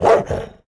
Minecraft Version Minecraft Version 1.21.5 Latest Release | Latest Snapshot 1.21.5 / assets / minecraft / sounds / mob / wolf / angry / bark1.ogg Compare With Compare With Latest Release | Latest Snapshot
bark1.ogg